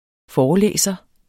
Udtale [ ˈfɒːɒˌlεˀsʌ ]